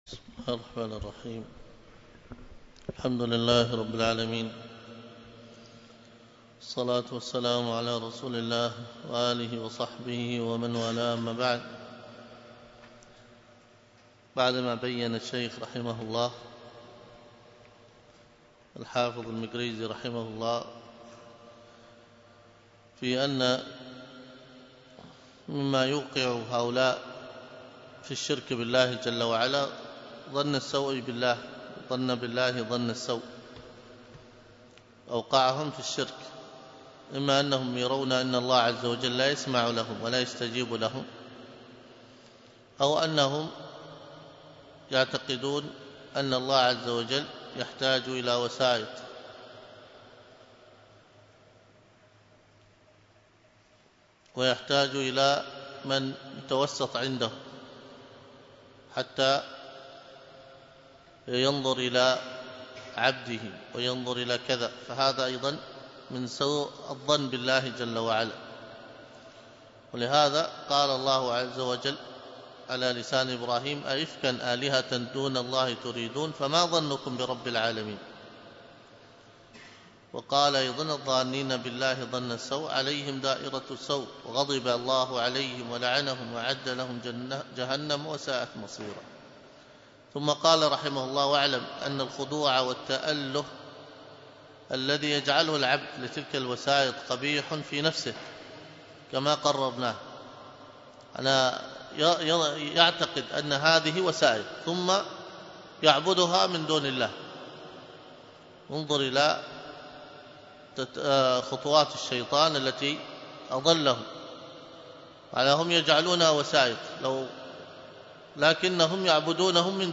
الدروس الحديث وعلومه